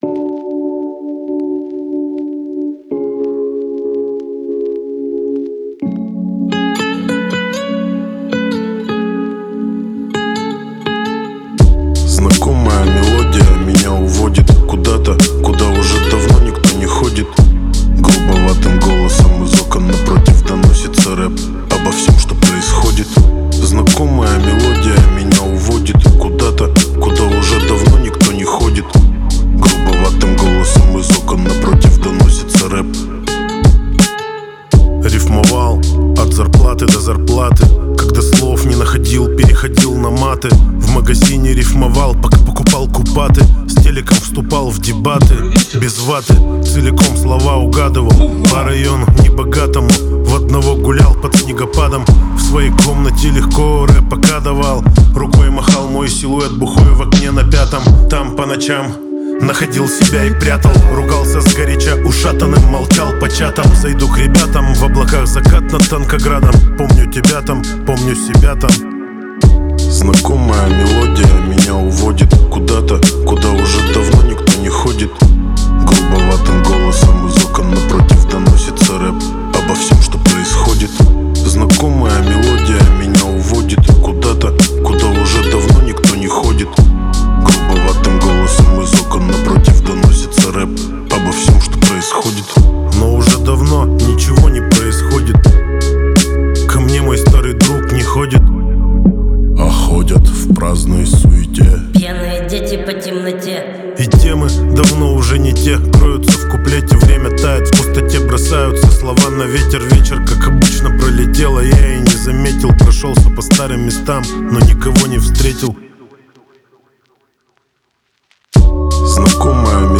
Жанр: Хип-хоп